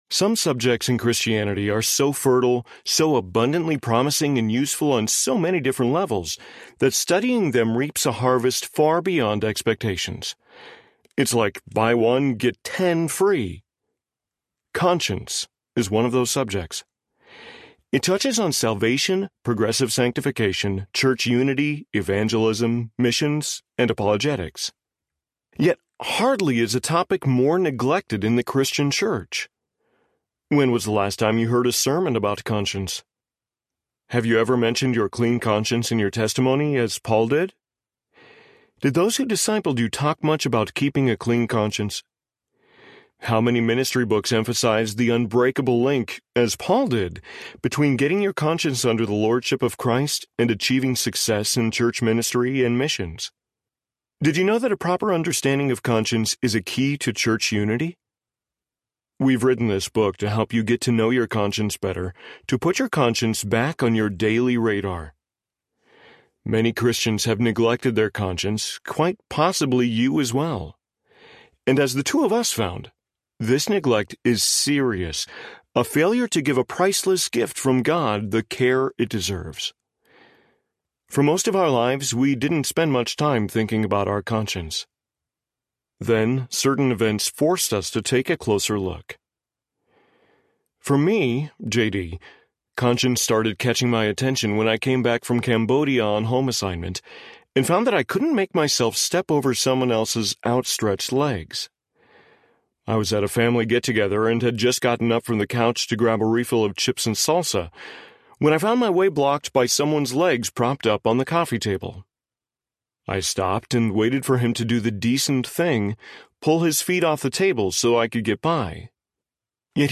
Conscience Audiobook
4.0 Hrs. – Unabridged